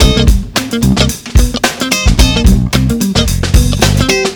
Funk Master Combo.wav